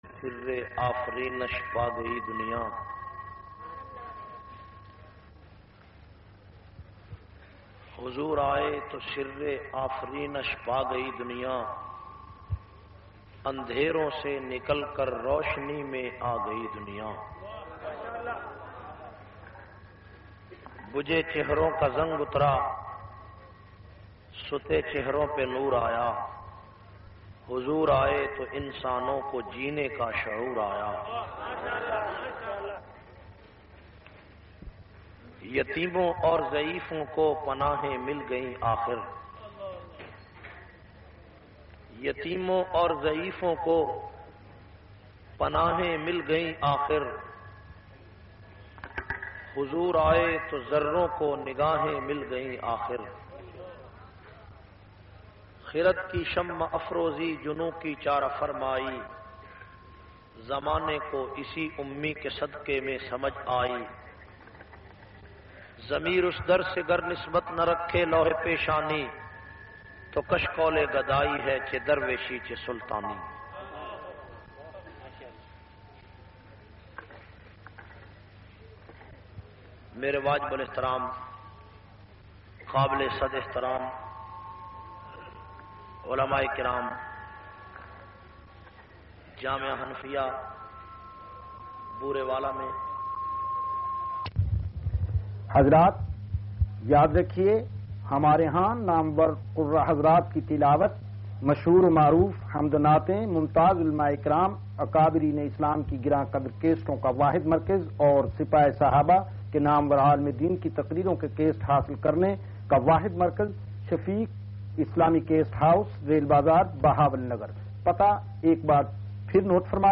602- Seerat Confrance Jamia Hanfia BureyWala.mp3